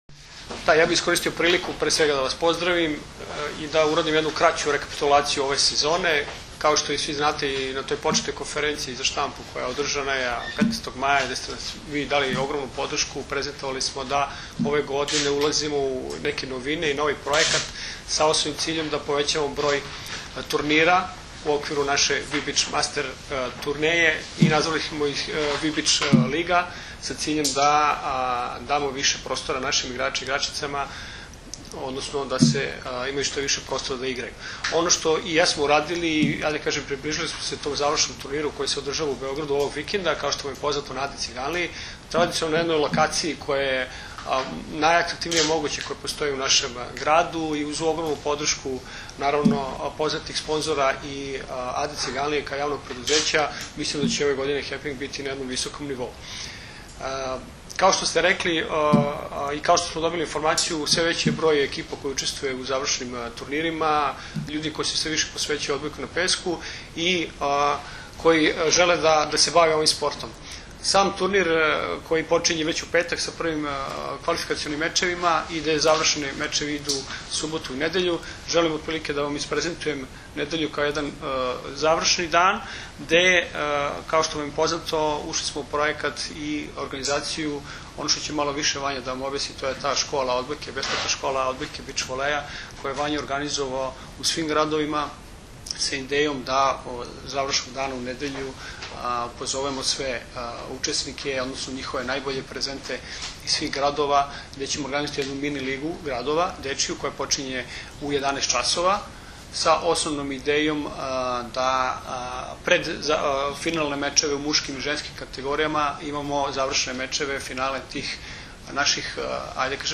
U prostorijama Odbojkaškog saveza Srbije danas je održana konferencija za novinare povodom Finalnog turnira 5. „Vip Beach Masters 2012. – Prvenstva Srbije u odbojci na pesku“, koji će se odigrati narednog vikenda na Adi Ciganliji, u Beogradu.
IZJAVA